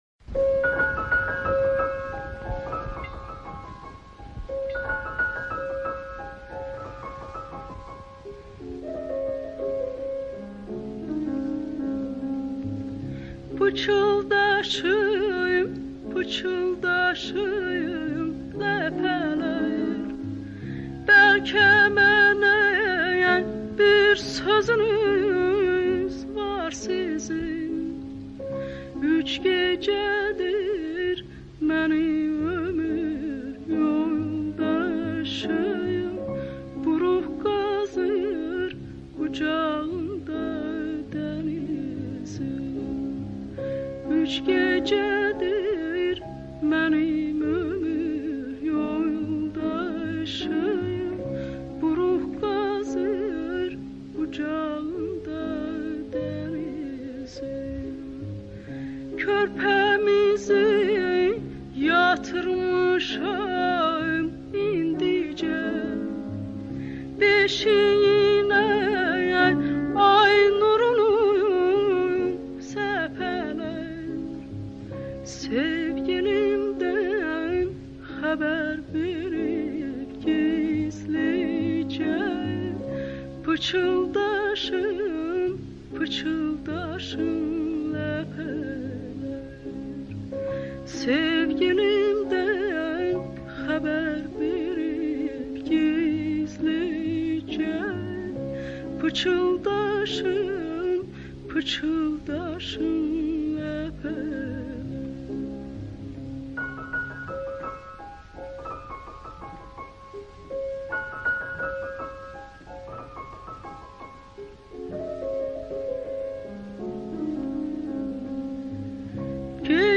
یکمی موسیقی اصیل :)